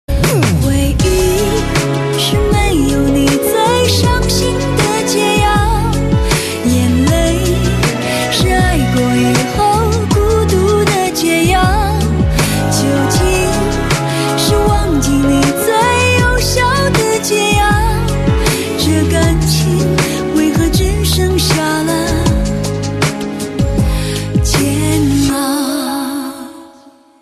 M4R铃声, MP3铃声, 华语歌曲 76 首发日期：2018-05-15 08:00 星期二